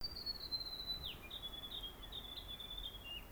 Gray gerygone | riroriro chirping Direct link to audio file
gray_gerygone.wav